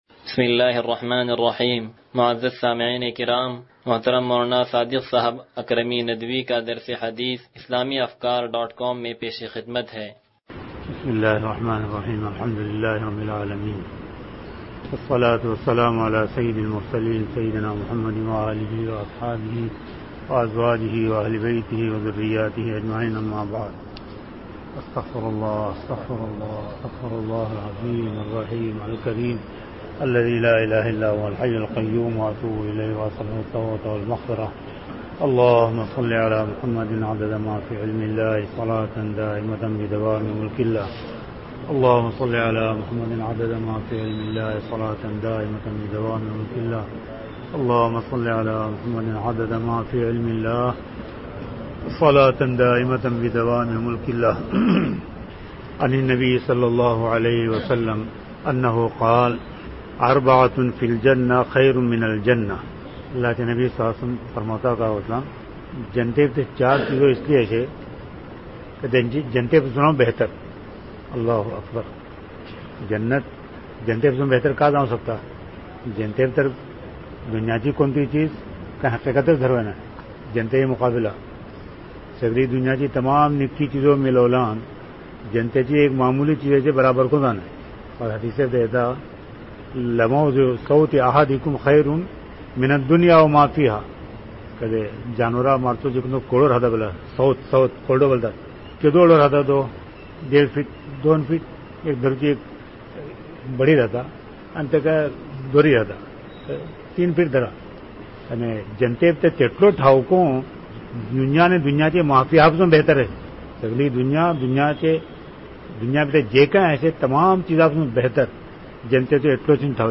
درس حدیث نمبر 0076